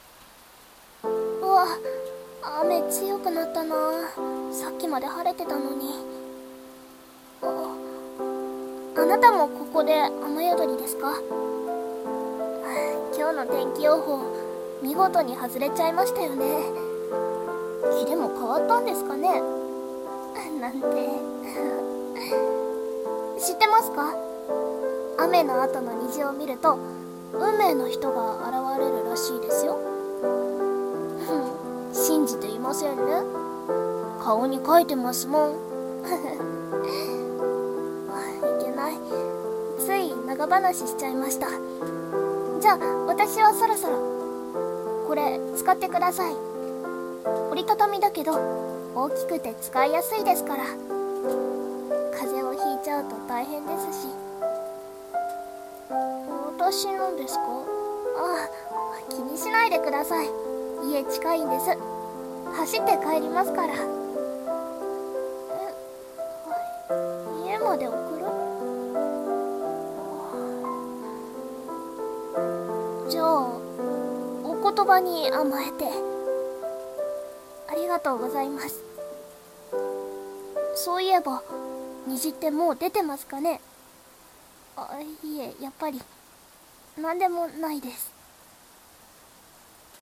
【声劇】 雨宿り